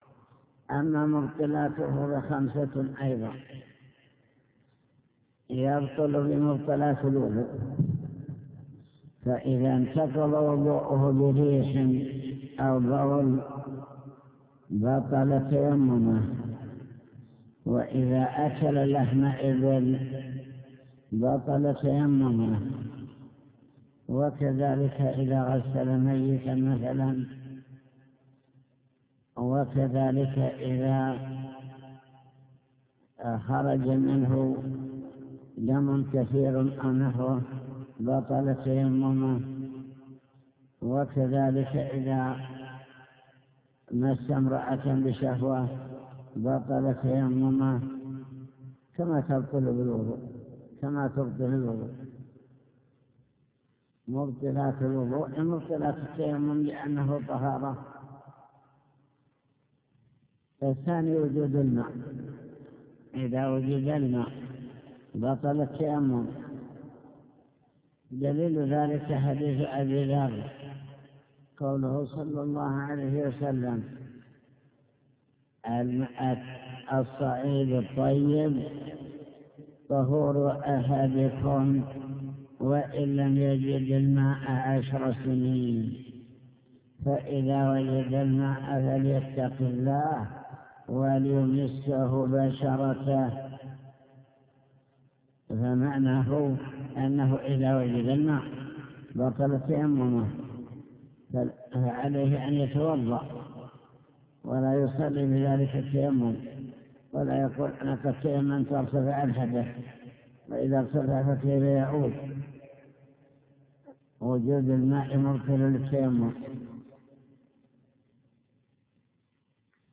المكتبة الصوتية  تسجيلات - كتب  شرح كتاب دليل الطالب لنيل المطالب كتاب الطهارة باب إزالة النجاسة